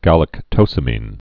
(gălək-tōsə-mēn, gə-lăk-)